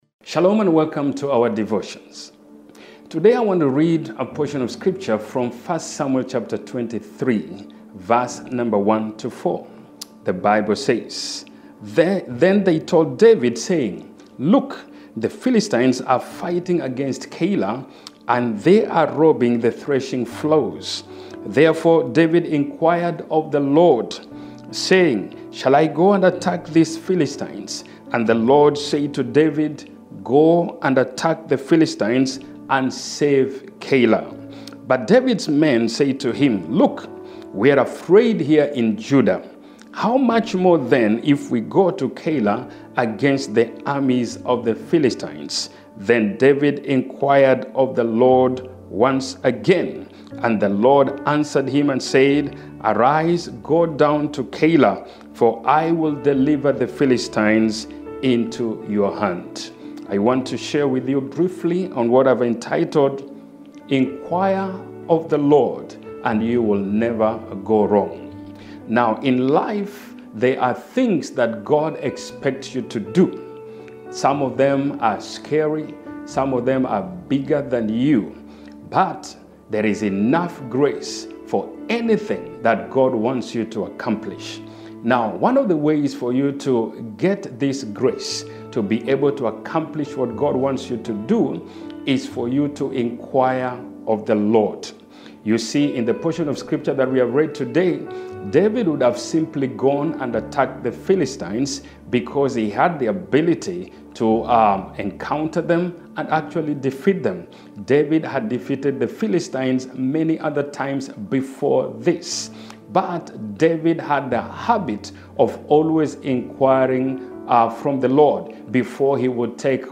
Morning Devotion